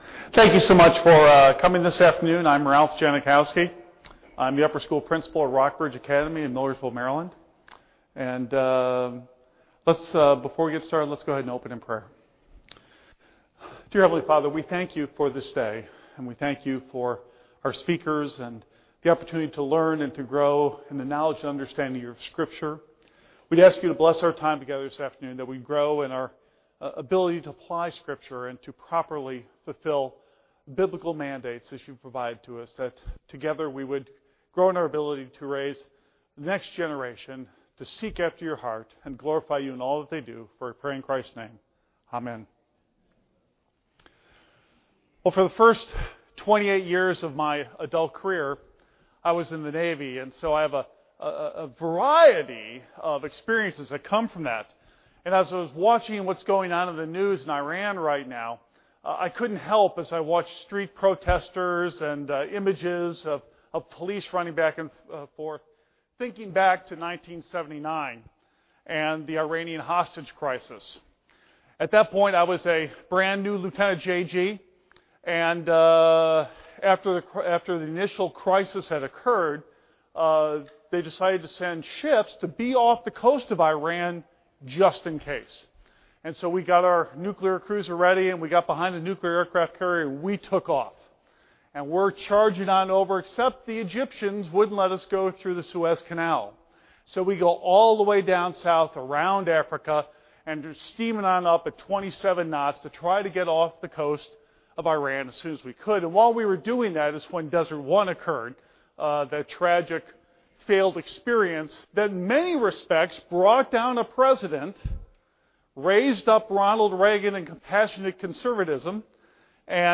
2009 Workshop Talk | 0:40:26 | Leadership & Strategic, Training & Certification